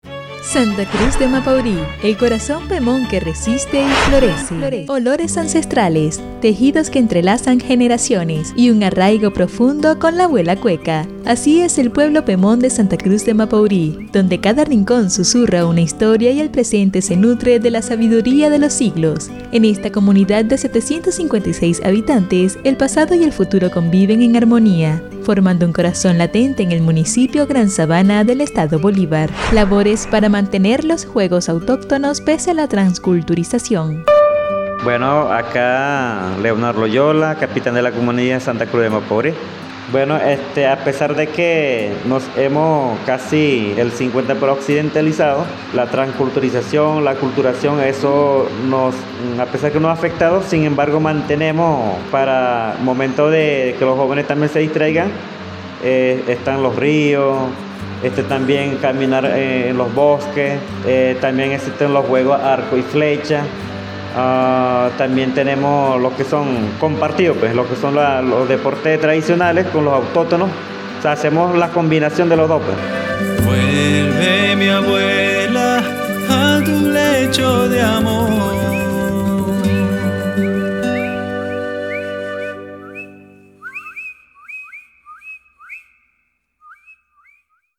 Micros radiales